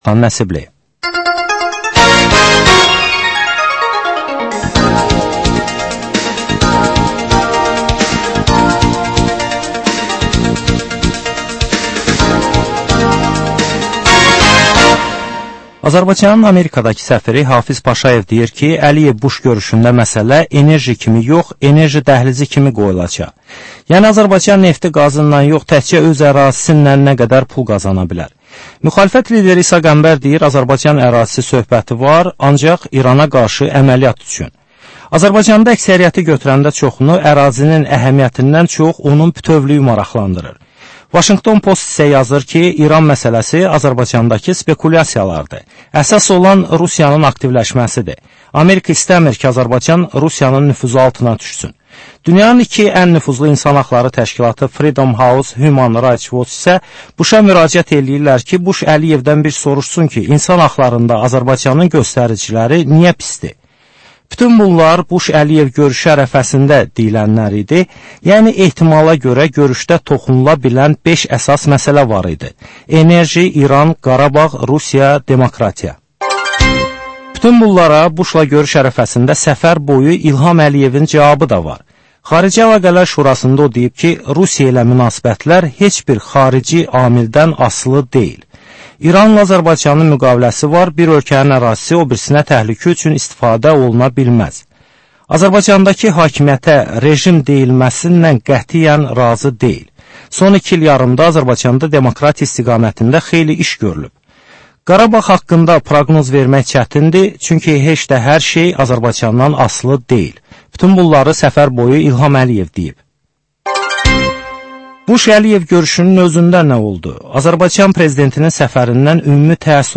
Dəyirmi masa söhbətinin təkrarı.